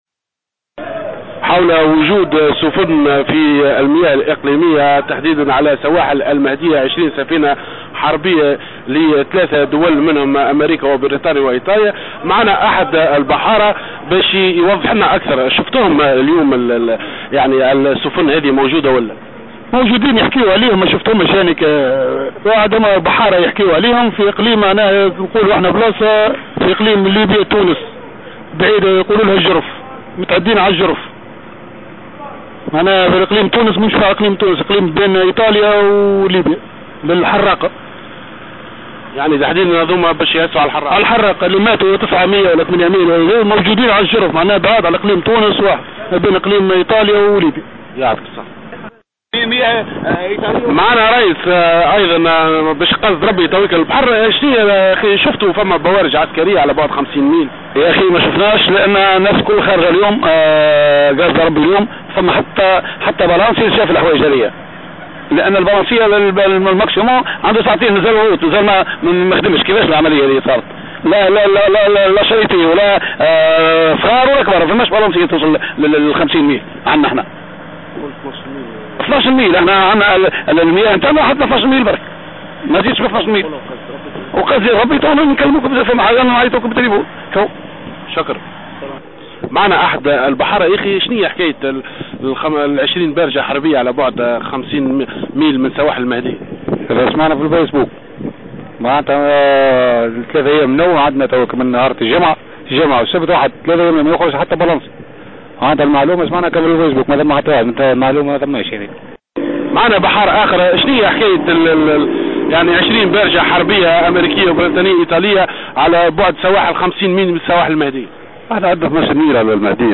سفن حربية قرب سواحل المهدية : شهادات عدد من البحارة